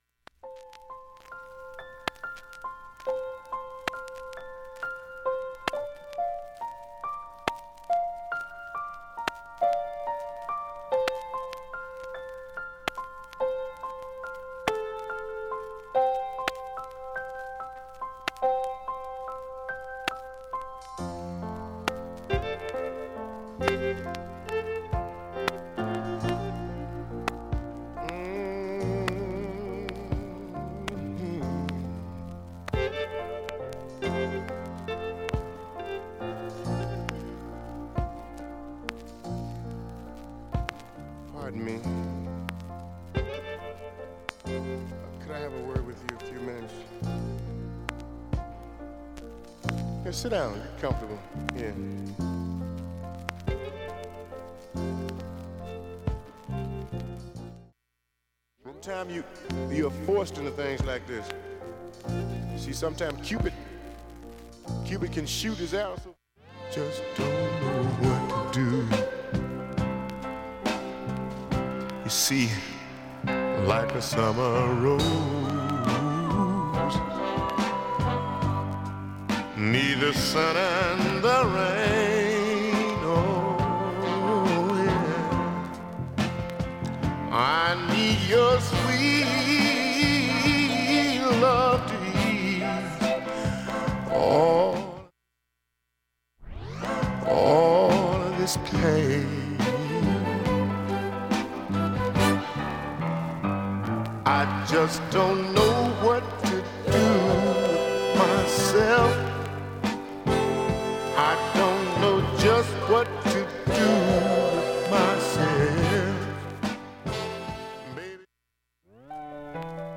チリ音、深いプツなどもありません
音質良好全曲試聴済み。
若干大きめに感じるプツが出ます。
A-1中盤にかすかなプツが３回出ます。
イントロのピアノからゆったりとしつつも
高揚感のあるホーンがカッコよく絡む